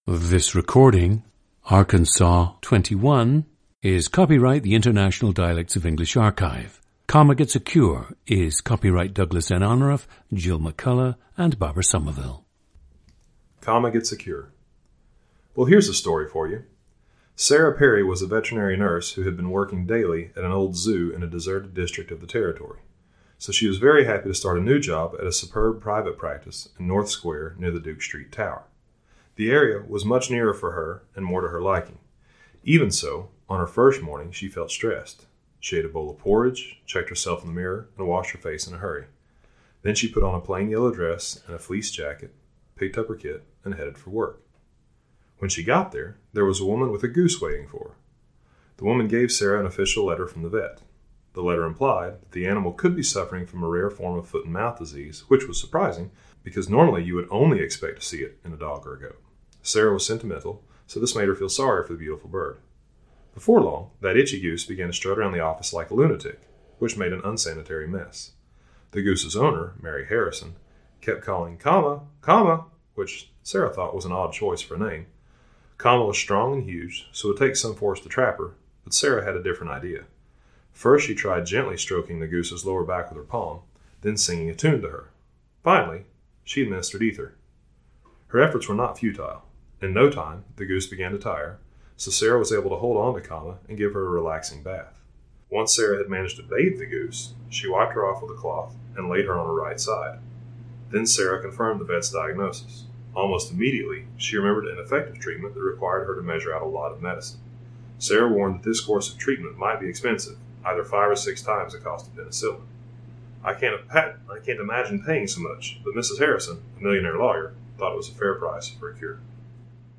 Listen to Arkansas 21, a 37-year-old man from Springdale, Arkansas, United States.
PLACE OF BIRTH: Springdale, Arkansas
GENDER: male
ORTHOGRAPHIC TRANSCRIPTION OF UNSCRIPTED SPEECH: